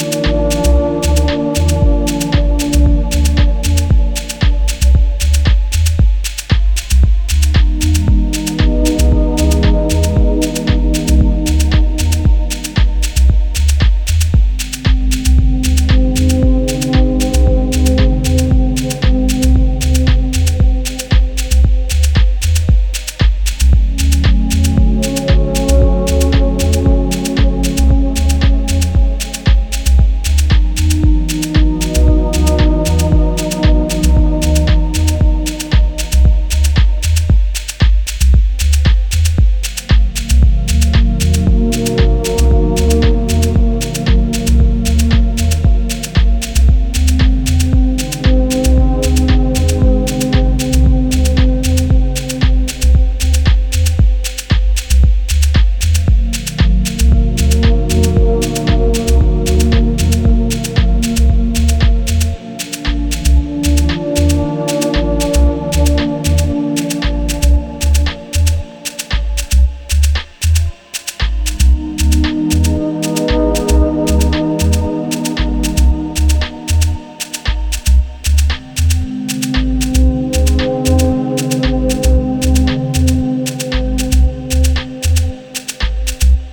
deeper than deep house